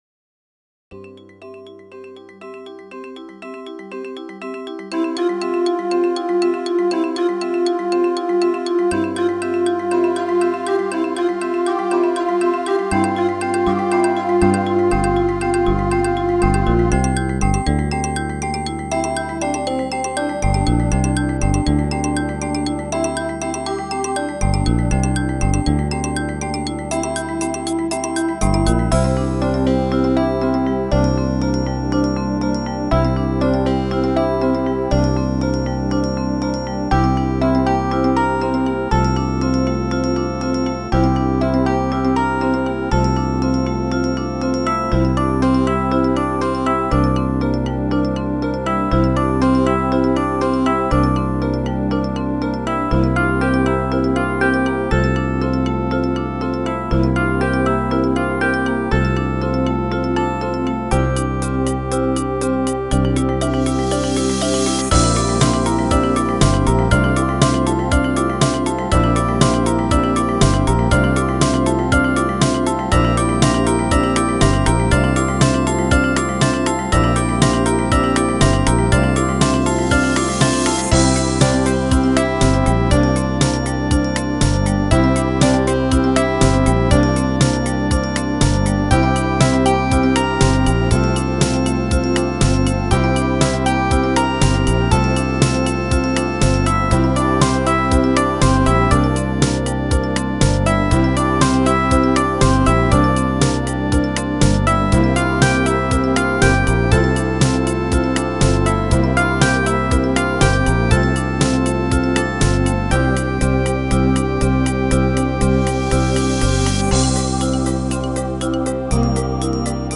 Theme suggesting tinkly jewels being created